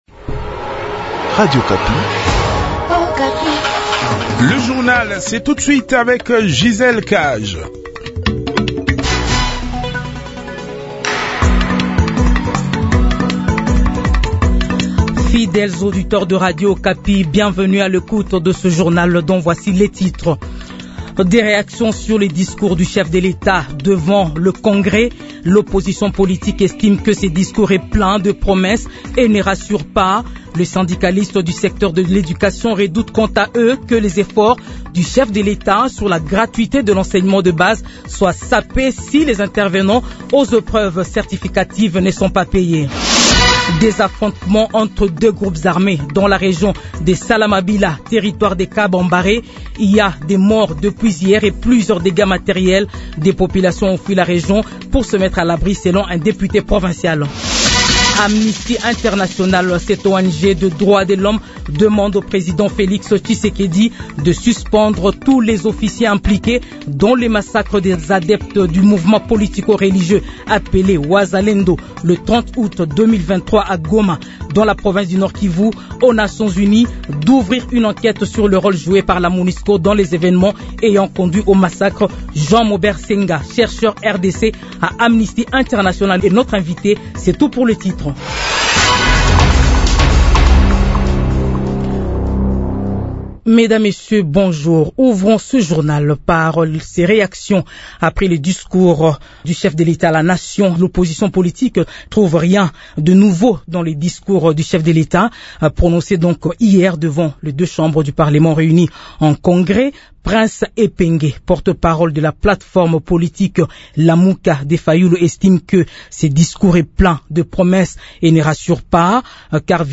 Journal 12 heures